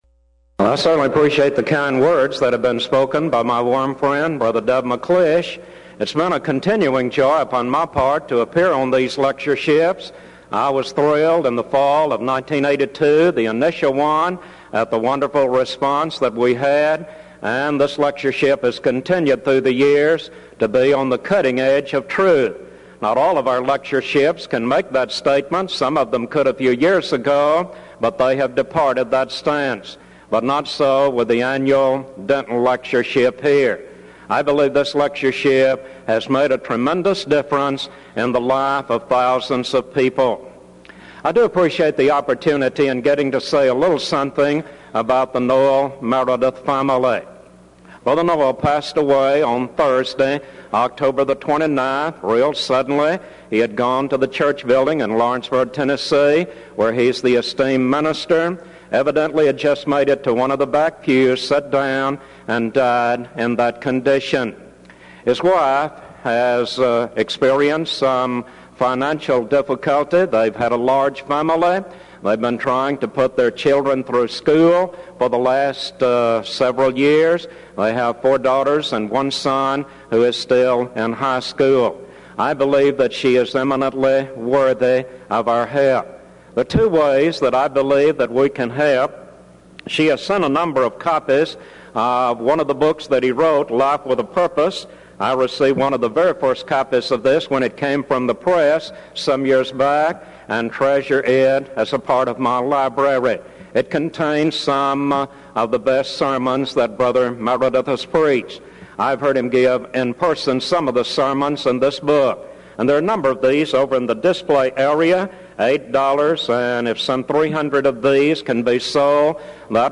Event: 1992 Denton Lectures Theme/Title: Studies In Ezra, Nehemiah And Esther